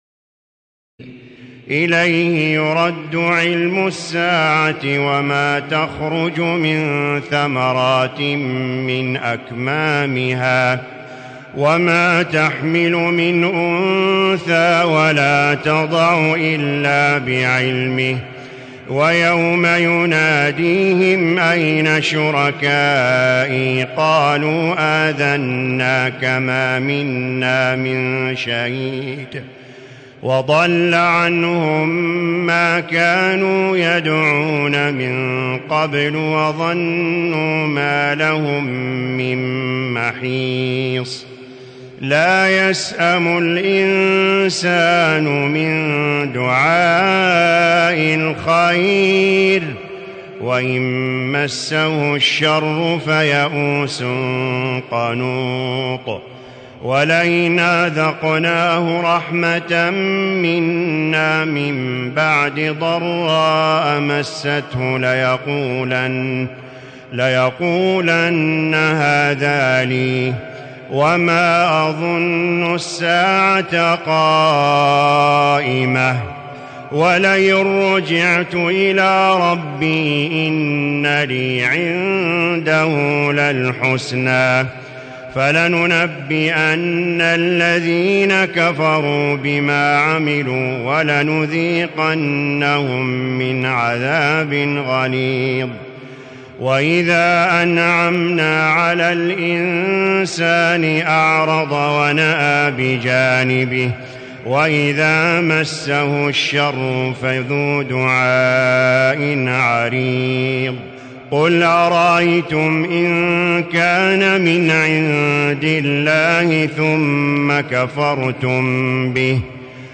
تراويح ليلة 24 رمضان 1437هـ من سور فصلت (47-54) و الشورى و الزخرف (1-25) Taraweeh 24 st night Ramadan 1437H from Surah Fussilat and Ash-Shura and Az-Zukhruf > تراويح الحرم المكي عام 1437 🕋 > التراويح - تلاوات الحرمين